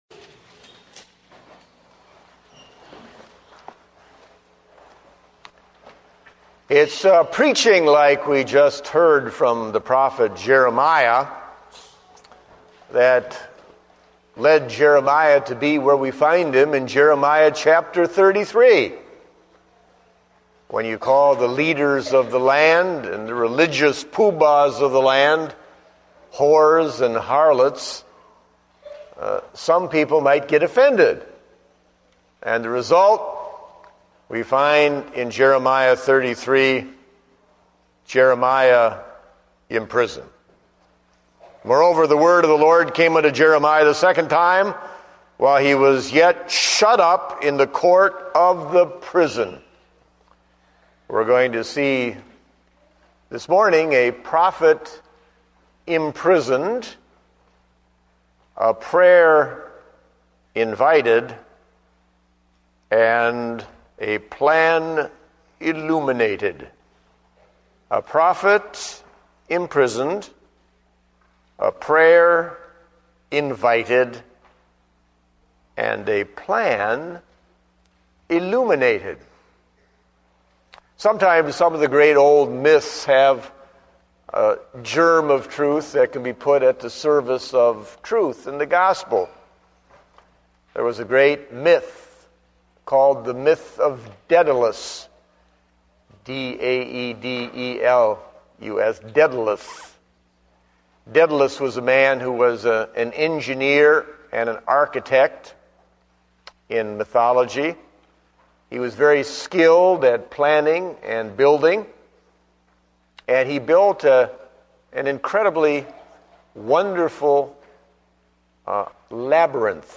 Date: November 8, 2009 (Morning Service)